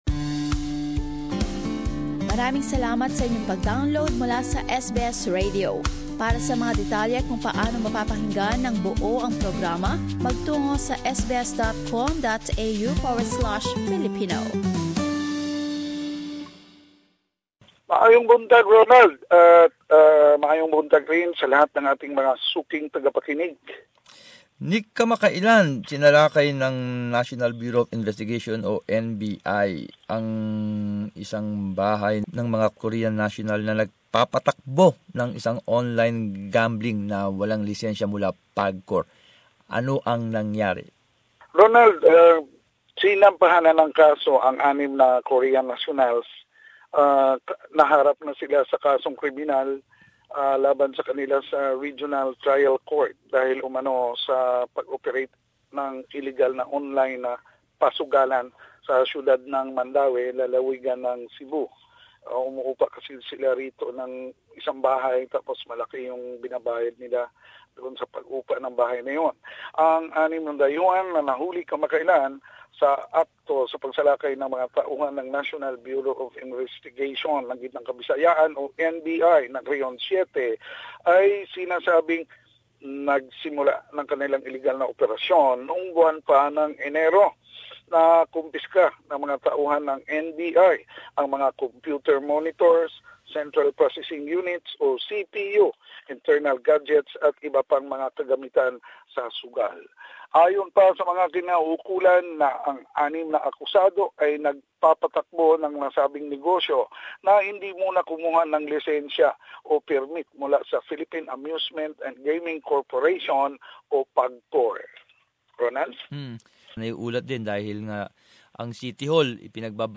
Balitang Bisayas. Summary of latest news from the region